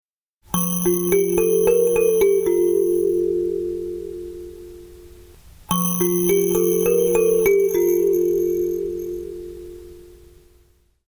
夢弦工房のハンドオルゴール
両手でもって親指でキーをはじくとオルゴールのような音がします。
キーの音が本体に共鳴してふんわりした音がします。
左右側面のトレモロホールを人差指でふさいだりあけたりすると音がウワウワ～とゆれてきれいです。
» トレモロホールを押さえながら演奏